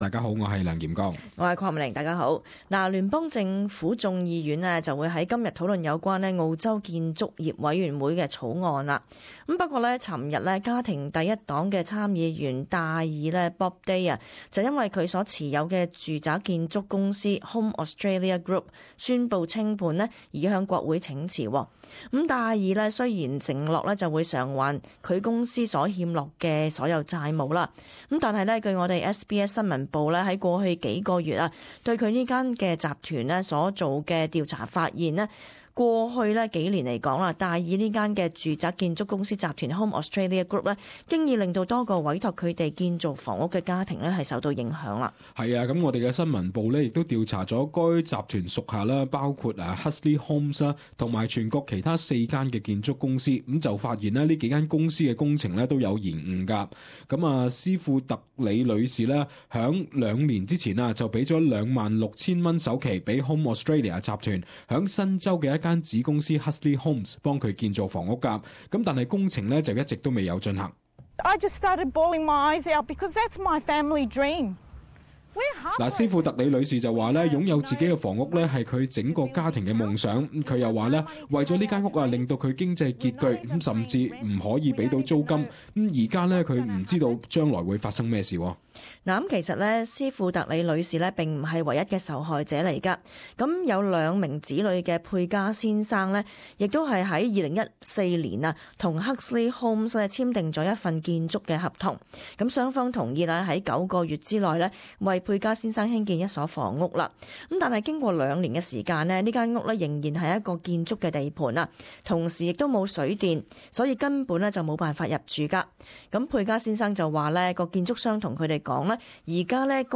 【時事報導】家庭第一黨參議員戴爾公司清盤